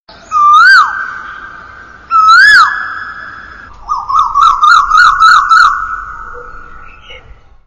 Copsychus saularis sound ringtone free download
Animals sounds